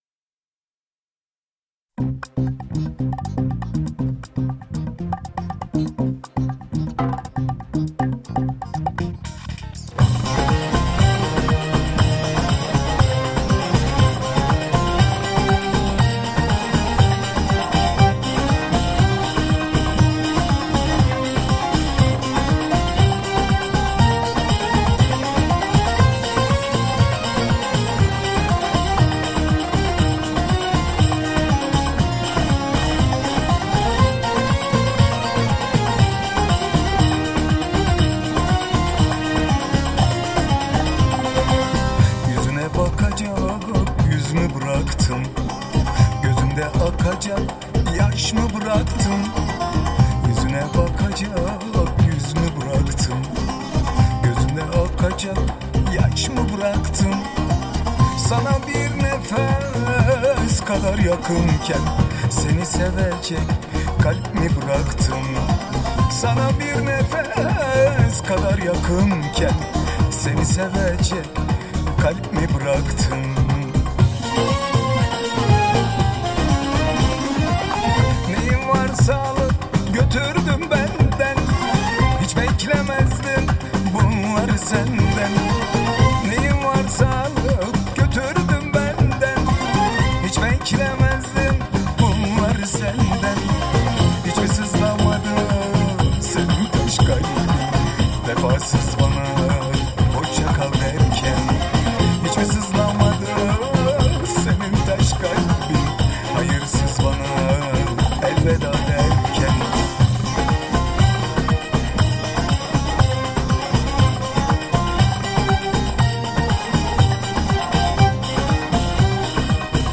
Pop Fantazi